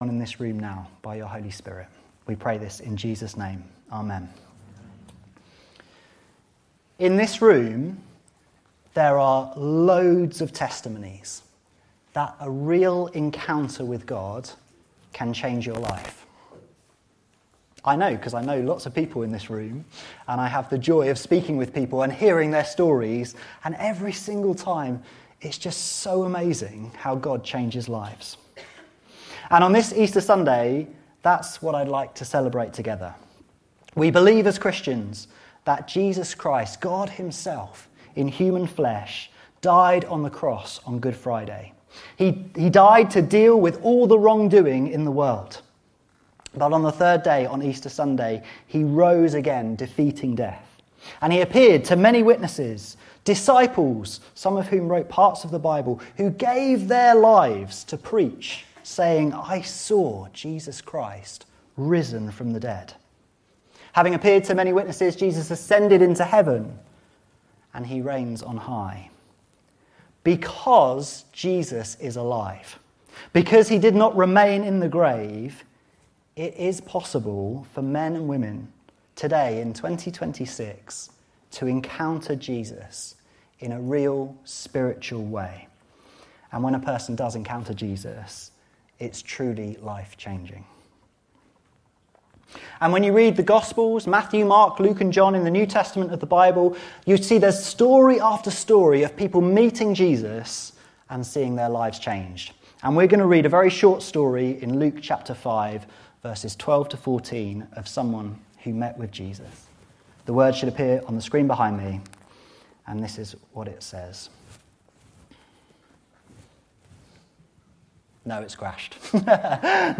This sermon centres on an encounter a man had with Jesus, the same Jesus who encounters people today.
*A few minutes of the sermon were not recorded due to technical difficulties.